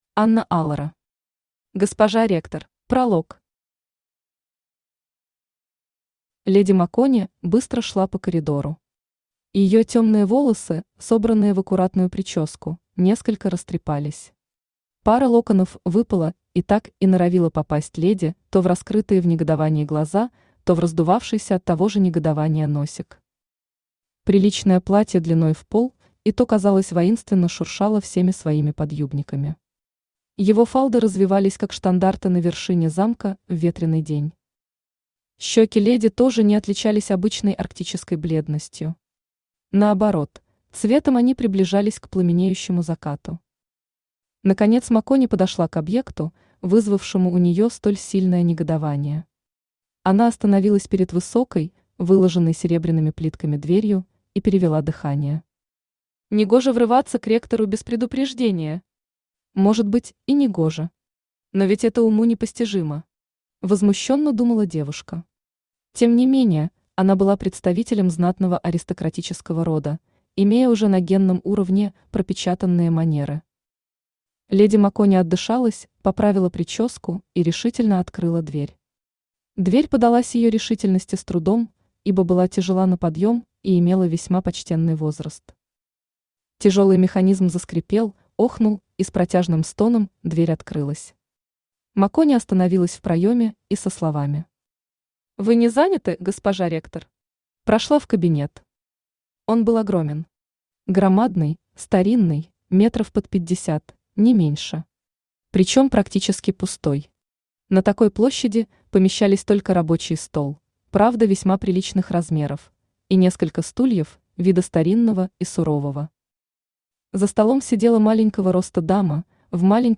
Аудиокнига Госпожа Ректор | Библиотека аудиокниг
Aудиокнига Госпожа Ректор Автор Анна Алора Читает аудиокнигу Авточтец ЛитРес.